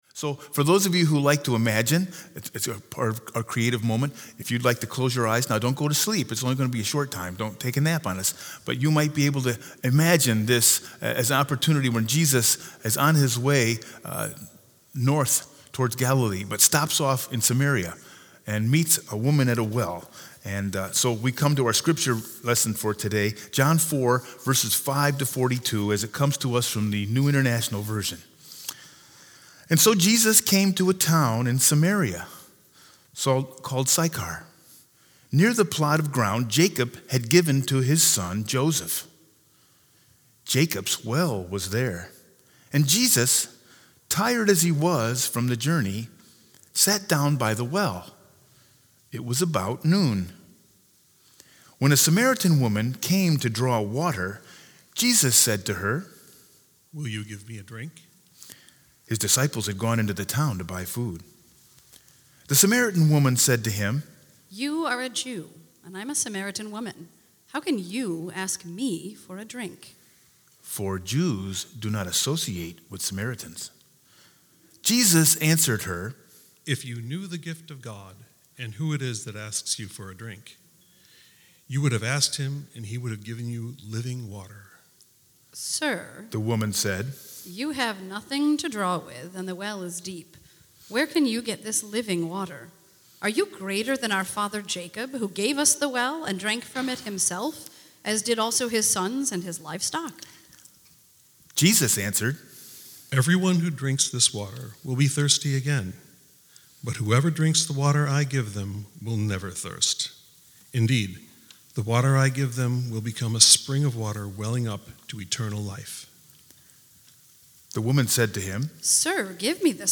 Sermon 3-15-20 with scripture lesson John 4_5-42